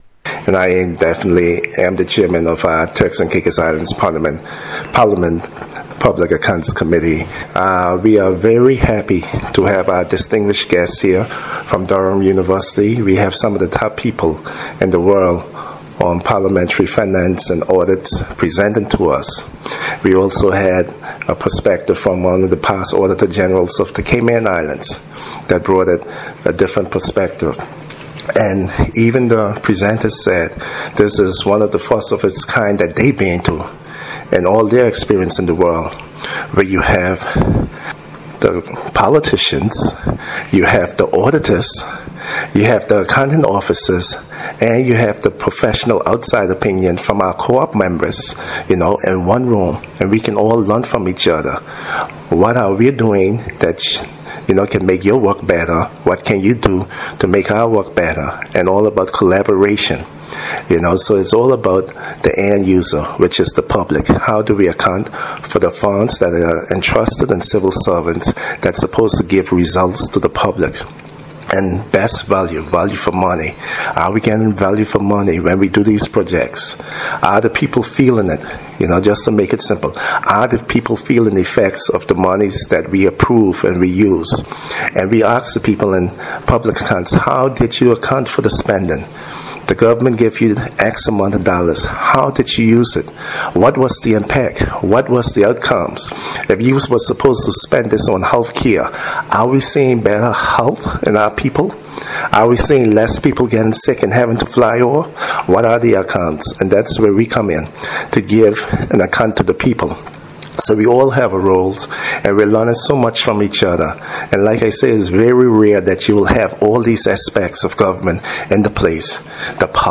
We spoke with the Leader of the Opposition Hon. Edwin Astwood about the significance of the workshop.